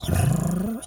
cat_2_purr_10.wav